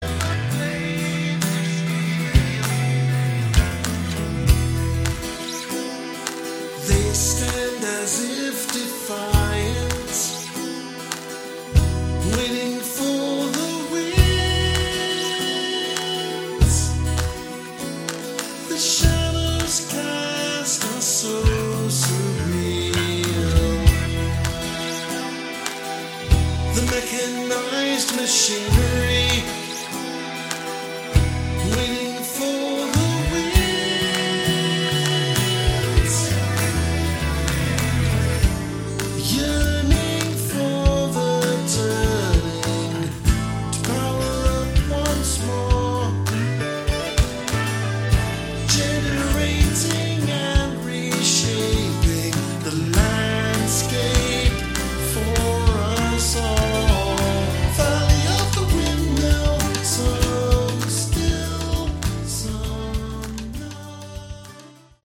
Category: Prog Rock
vocals, guitars
keyboards, hammond organ
drums, percussion